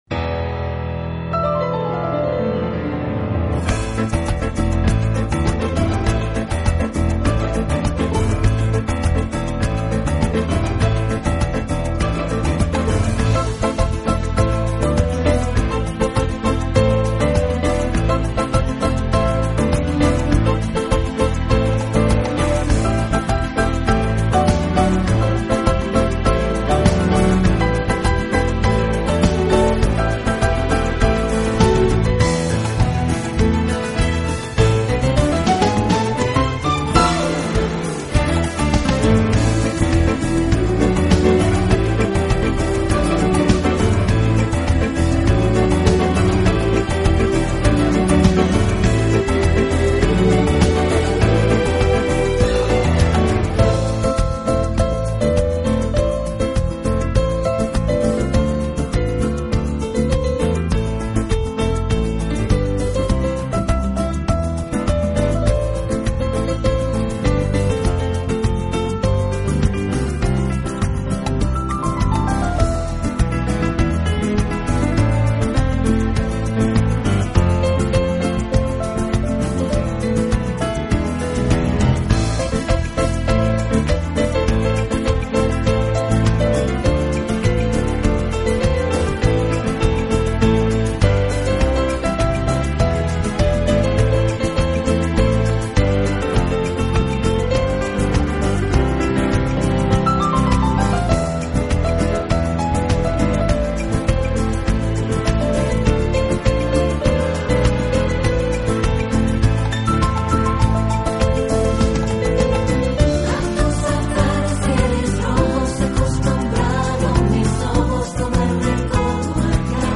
Genre: Latin Piano